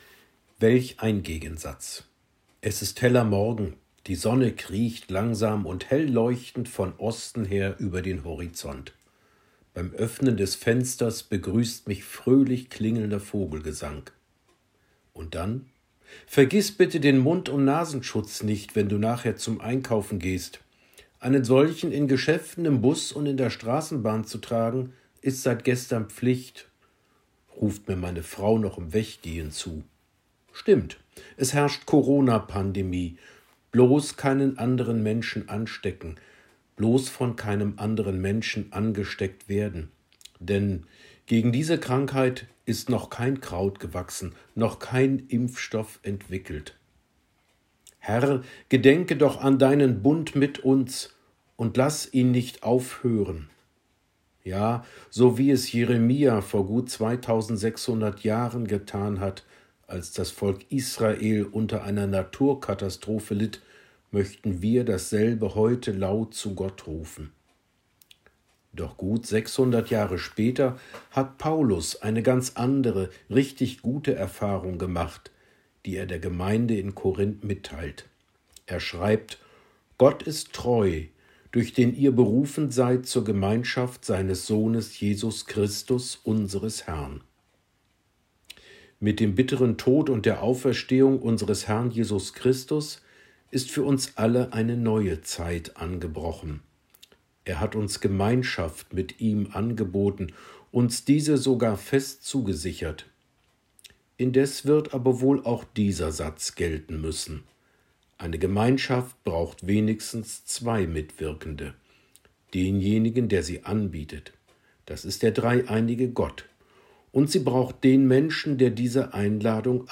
Andachten zum Hören und Lesen von Mitarbeitenden der Evangelischen Landeskirche Anhalts